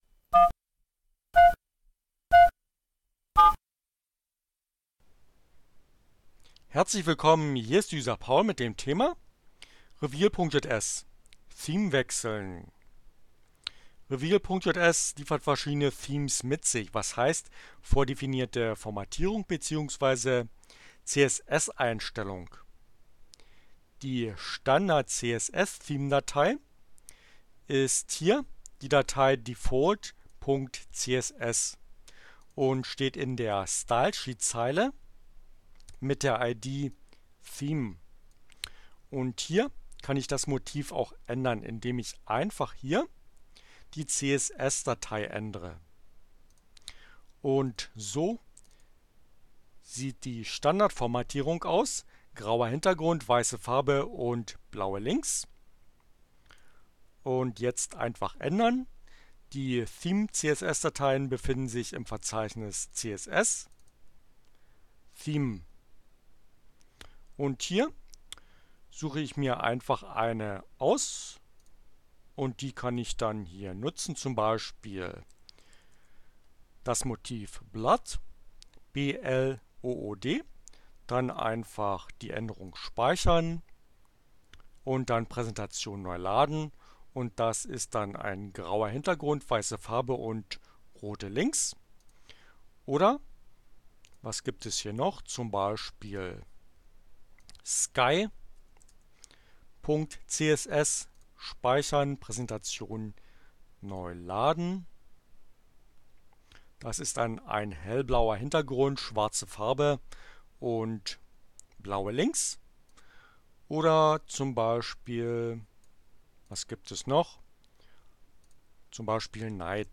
Tags: CC by, Linux, Neueinsteiger, ohne Musik, screencast, Web, HTML, revealjs